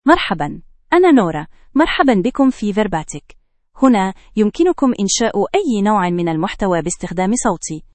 FemaleArabic (Standard)
Nora is a female AI voice for Arabic (Standard).
Voice sample
Listen to Nora's female Arabic voice.
Nora delivers clear pronunciation with authentic Standard Arabic intonation, making your content sound professionally produced.